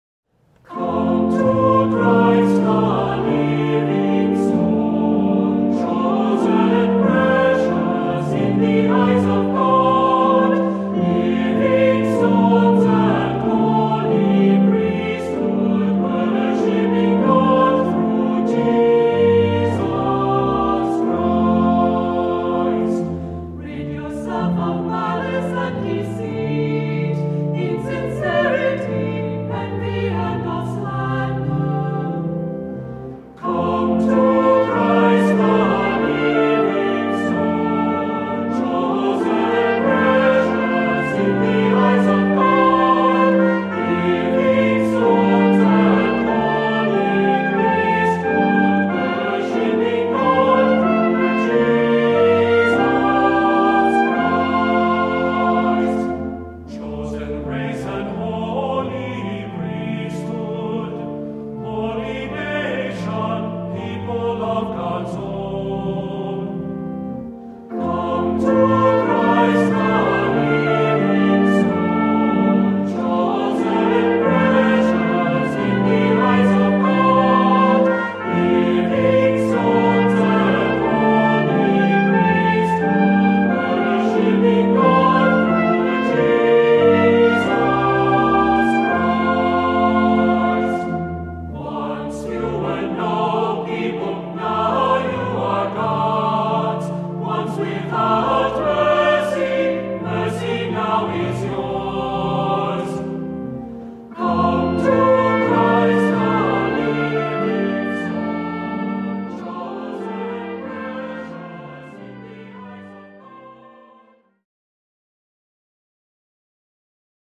Voicing: Cantor, assembly